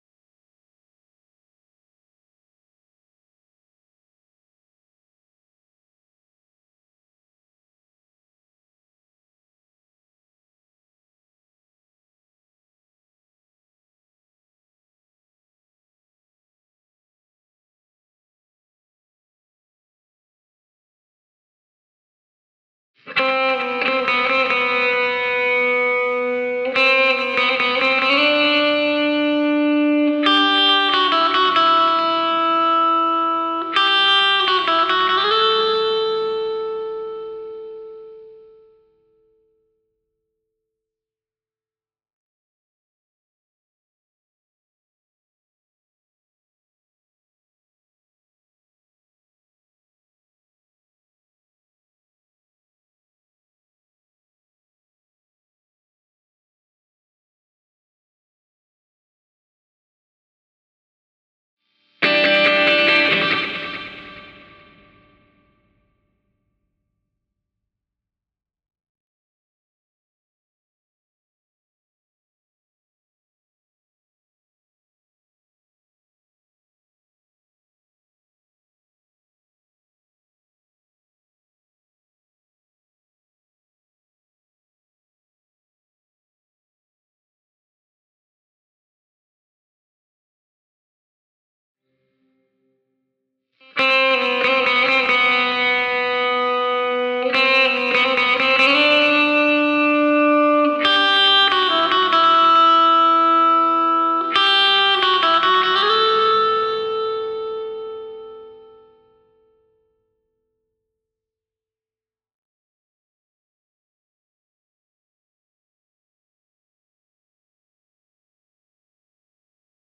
Faith - Reaper Remix_freeze_Lead Guitar 1-001.wav